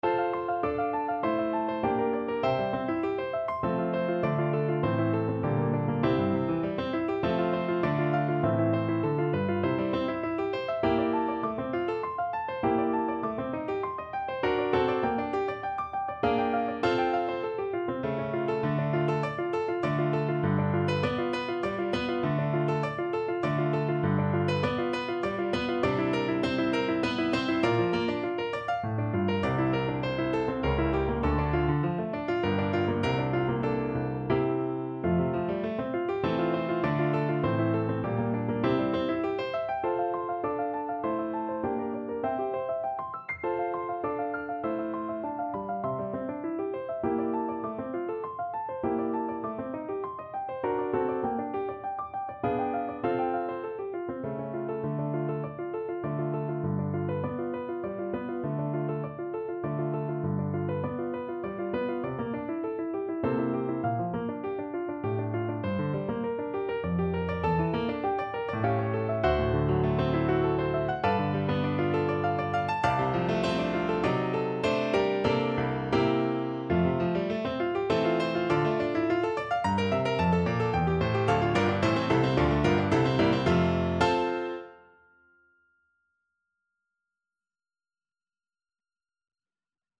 ~ = 100 Very quick and passionate
3/4 (View more 3/4 Music)
Classical (View more Classical Soprano Voice Music)